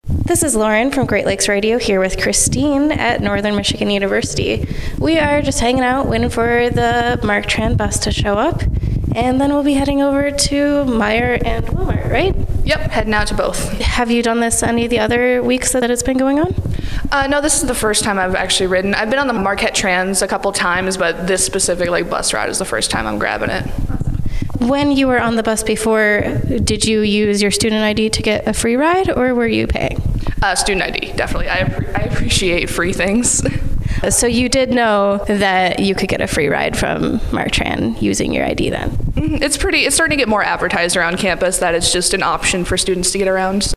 Hear what the students and staff at NMU think: